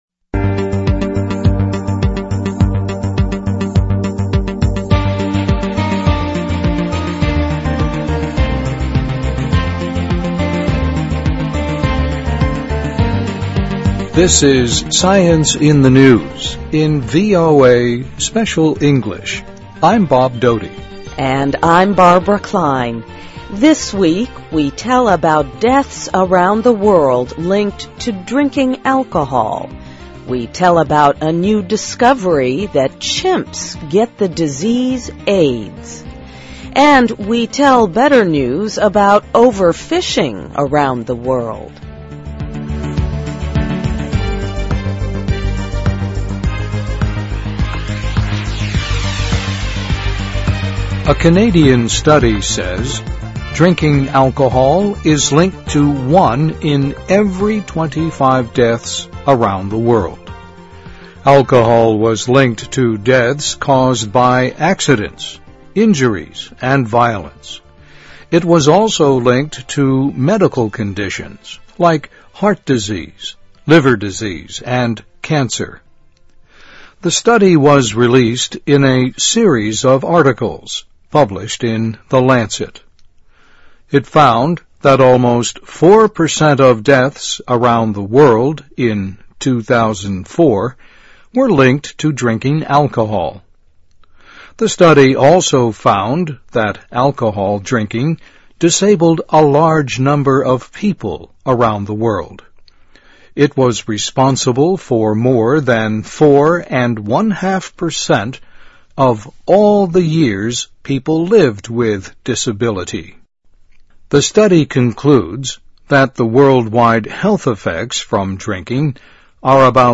VOA慢速英语2009年-SCIENCE IN THE NEWS - Alcohol Linked to On 听力文件下载—在线英语听力室